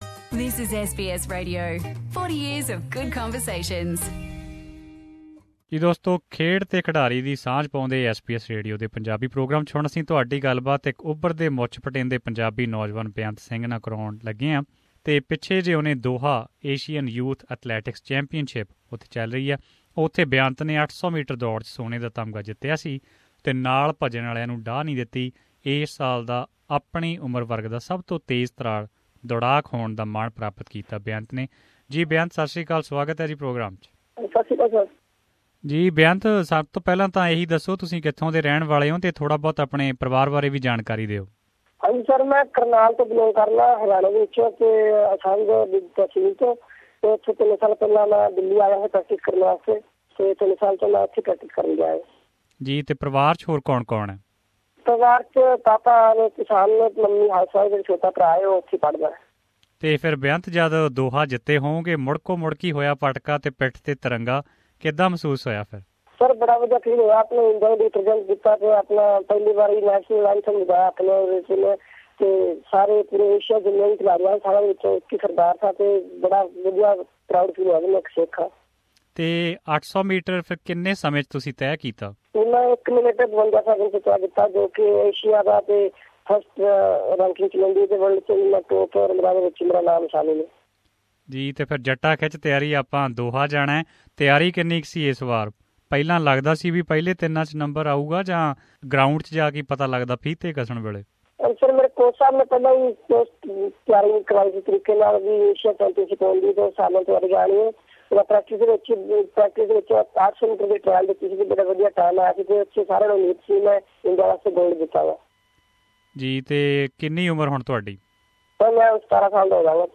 In this short conversation